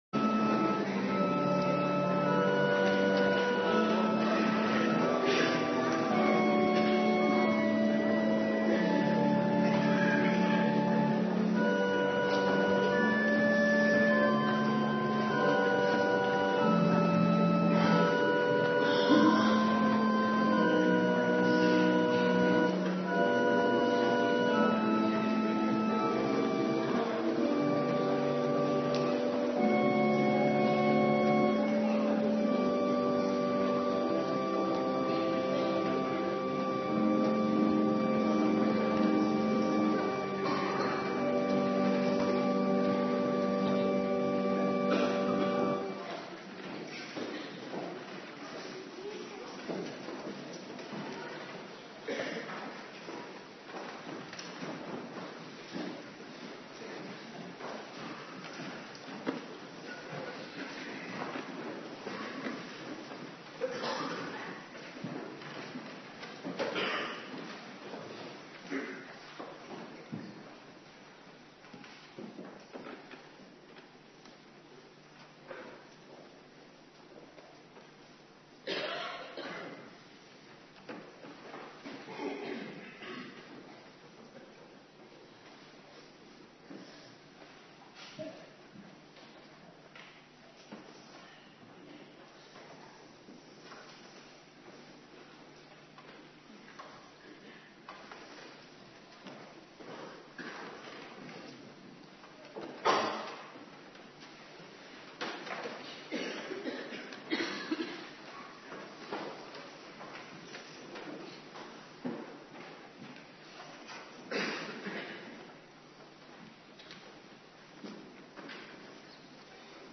Morgendienst Hemelvaartsdag
09:30 t/m 11:00 Locatie: Hervormde Gemeente Waarder Agenda